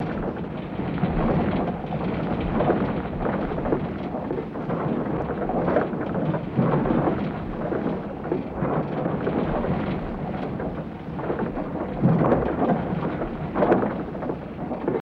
Tires And Squeaks Like Wagon